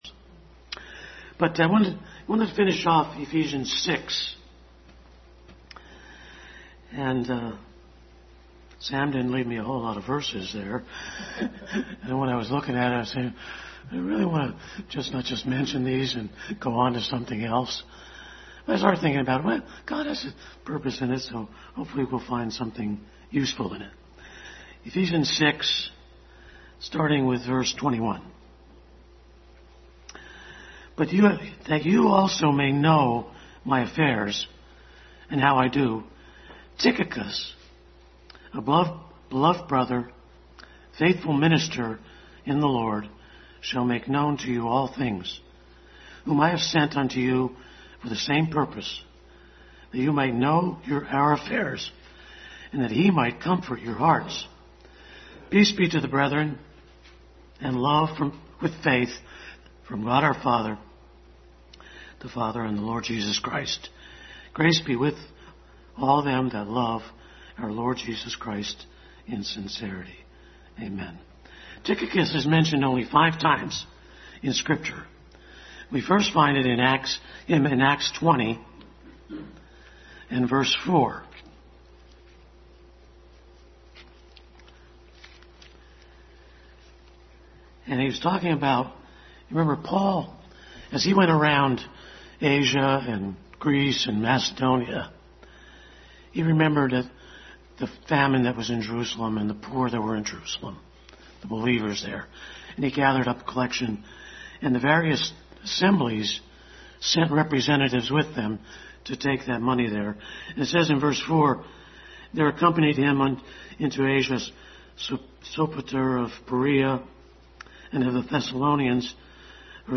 2 Kings 7:3-11 Service Type: Family Bible Hour Conclusion of our study in Ephesians.